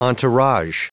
Prononciation du mot entourage en anglais (fichier audio)
Prononciation du mot : entourage